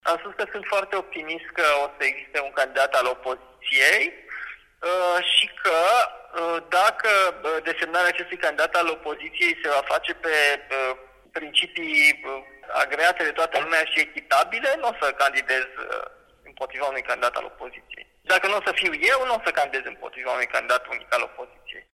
Despre susținerea obținută, Nicușor Dan a declarat într-un interviu acordat Europei Libere, că „prin votul acesta, USR-ul și-a respectat obiectivul pentru care s-a constituit: acela de a extrage competențe din societate indiferent de apartenența politică.